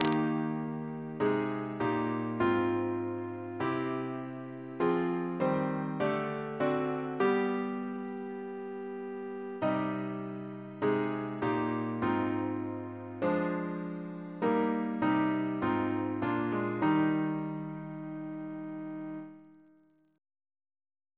Double chant in E♭ Composer: Sir Ivor Algernon Atkins (1869-1953), Organist of Worcestor Cathedral Reference psalters: ACB: 180; ACP: 159; RSCM: 5